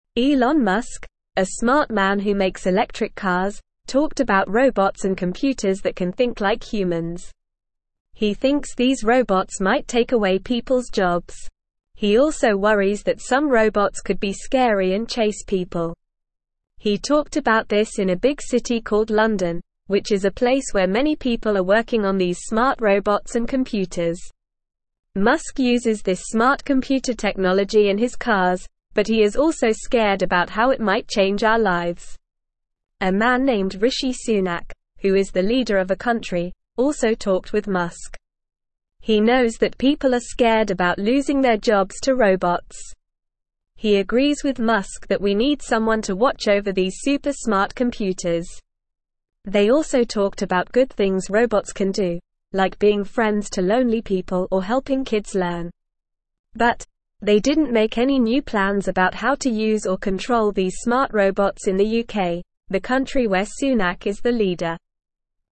Normal
English-Newsroom-Beginner-NORMAL-Reading-Elon-Musk-and-Rishi-Sunak-Discuss-Smart-Robots.mp3